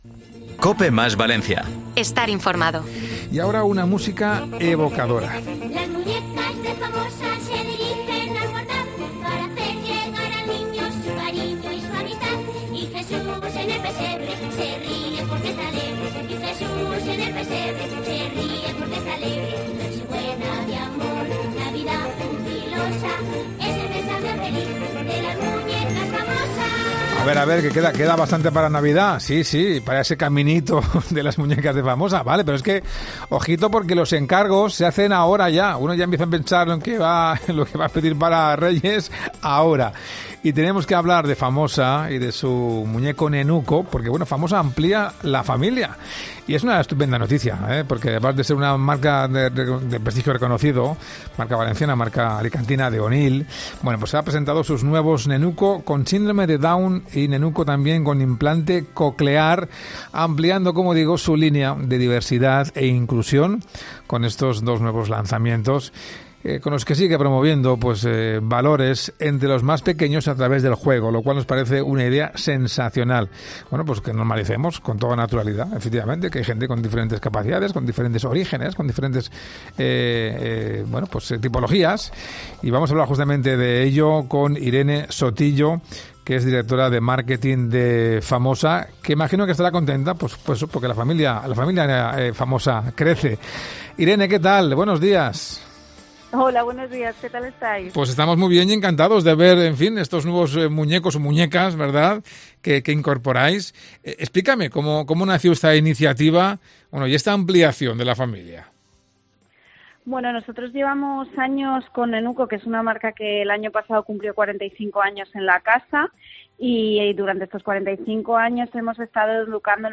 ENTREVISTA | Así son las nuevas muñecas con síndrome de Down e implante coclear